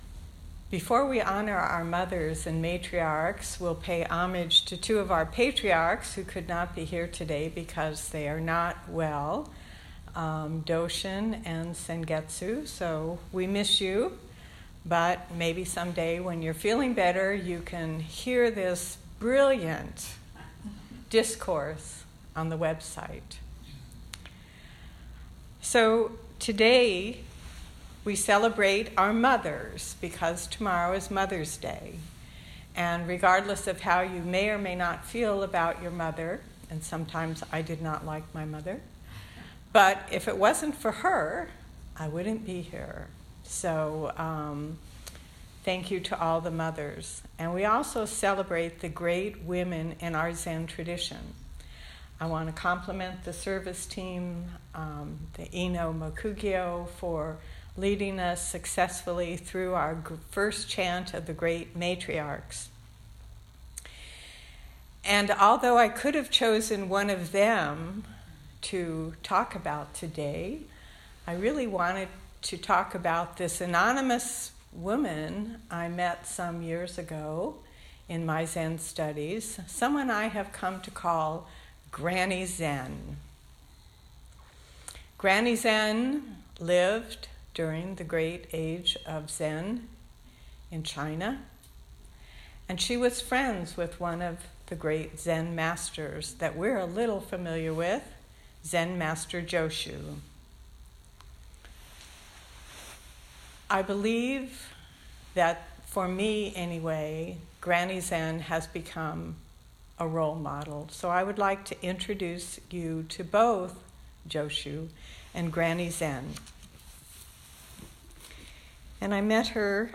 May, 2018 Southern Palm Zen Group